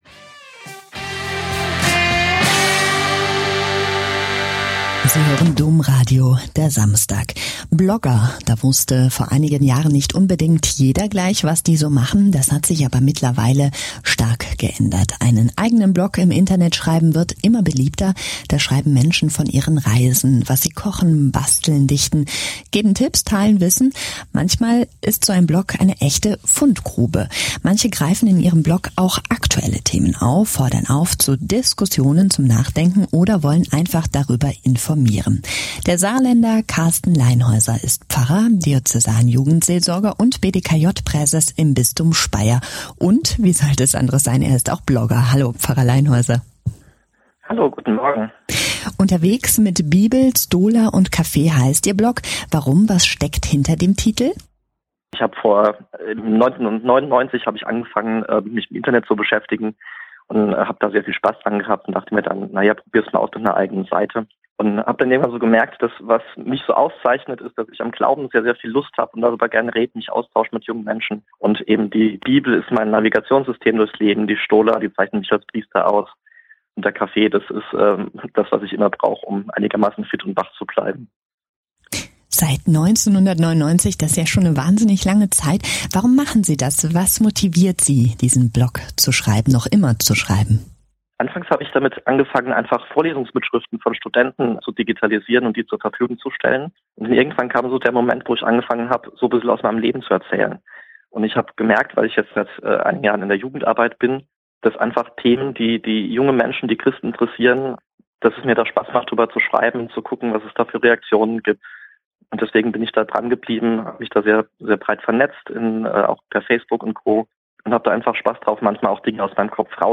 Das Domradio hat mich angerufen, um über’s Bloggen zu sprechen. Hier könnt Ihr das Live-Interview nachhören: